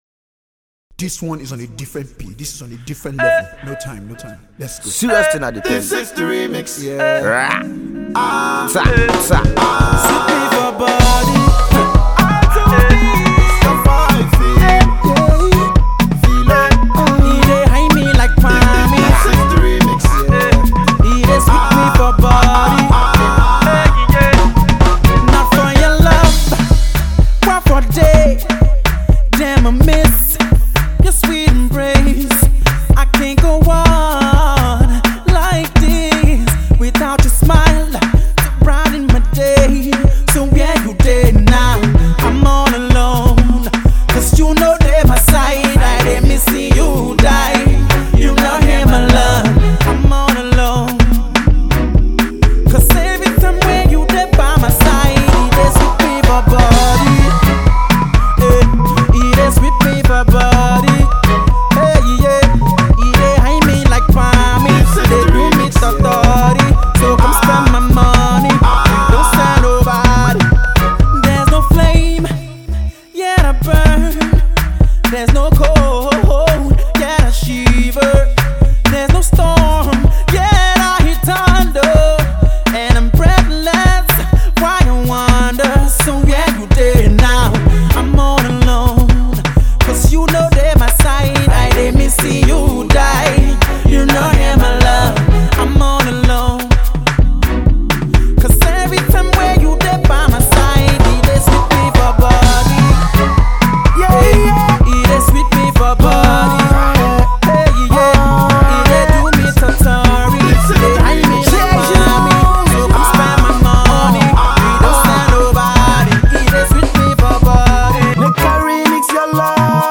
with a remix to his new Dance track
to the bill to make for an energetic dance experience.